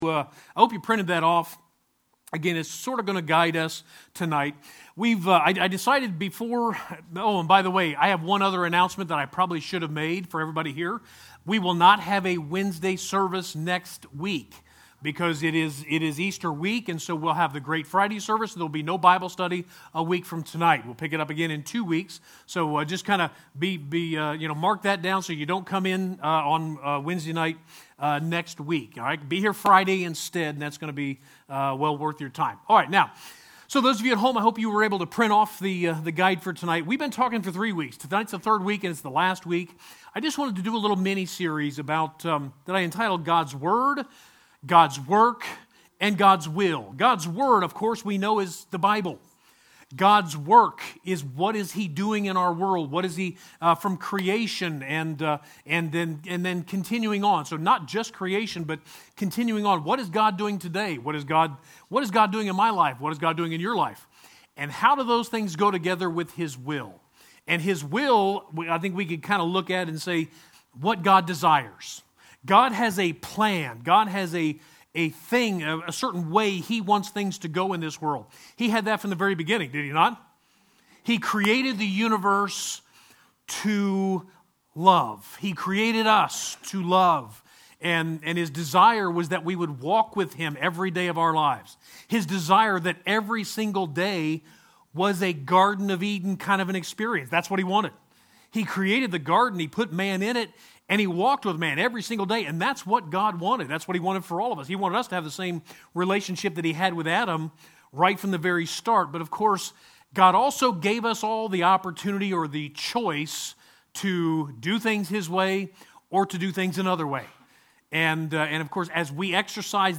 3-24-21 Wednesday Bible Study: God’s Word, God’s Work, and God’s Will #3